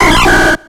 Cri de Qwilfish dans Pokémon X et Y.